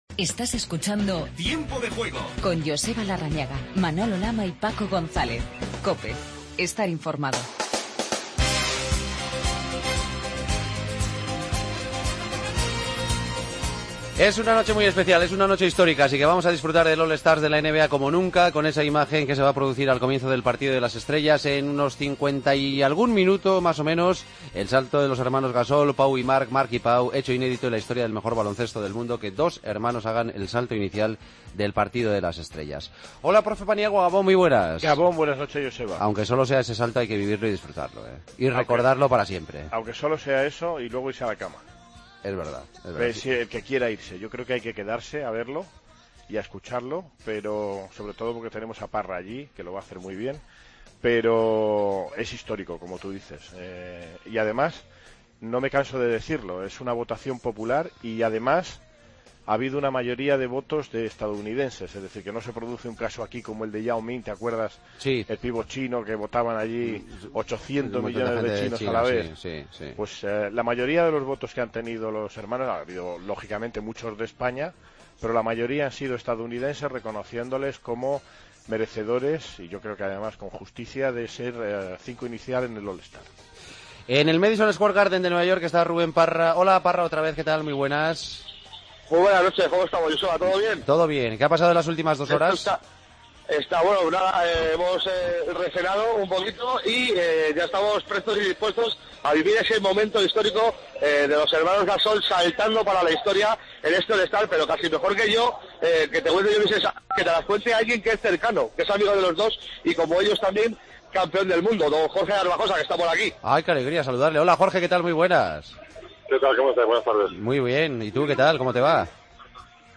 Paso por Nueva York a pocos minutos del gran salto de los hermanos Gasol en el All-Star. Entrevista a Jorge Garbajosa.